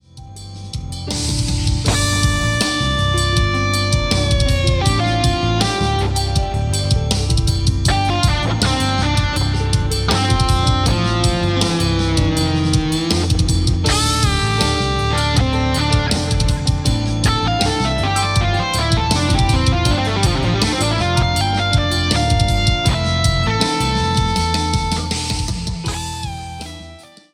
raw - mix - master
mix
aruna-MIX.wav